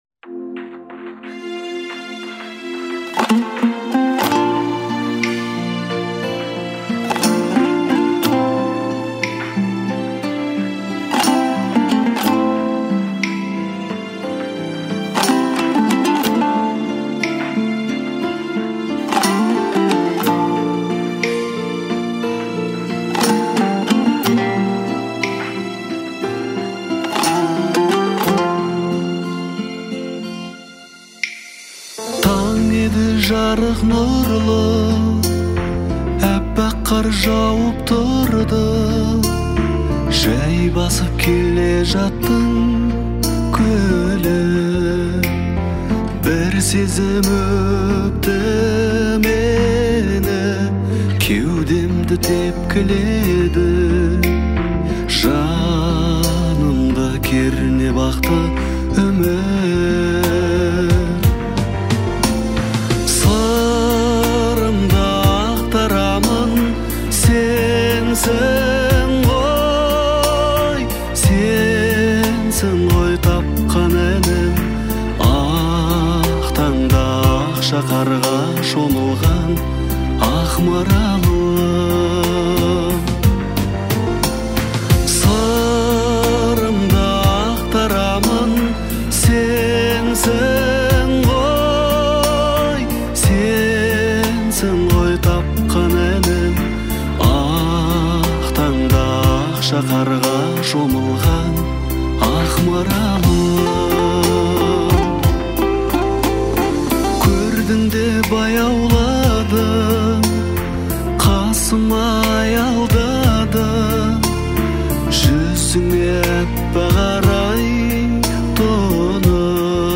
это трогательная песня в жанре казахской поп-музыки
выразительным вокалом